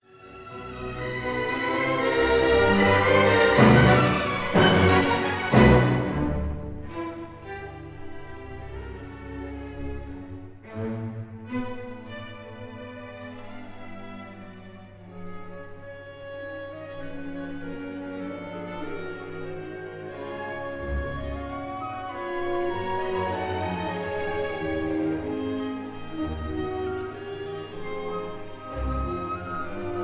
1956 live performance